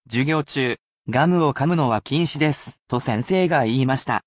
Word of the Week is special in that you do not simply learn a Japanese word or phrase that I will pronounce for you personally, but you can absorb so many other morsels of knowledge.